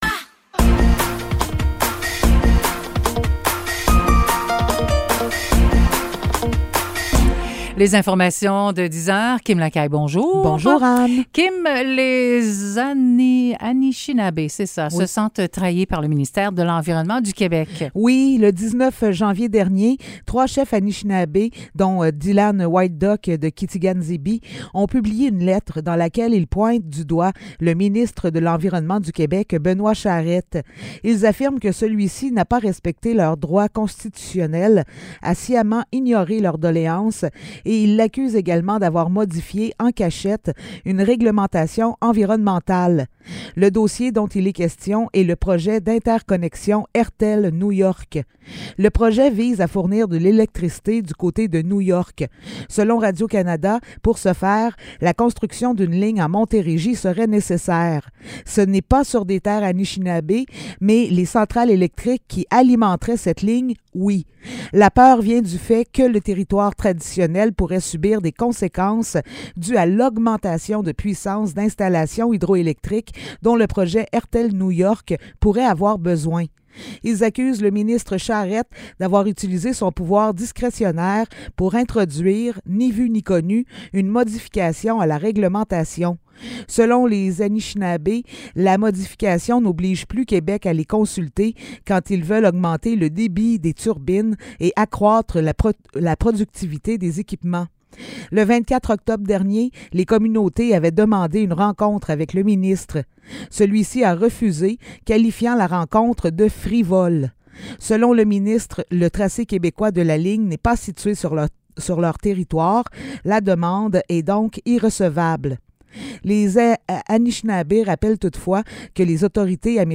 Nouvelles locales - 25 janvier 2023 - 10 h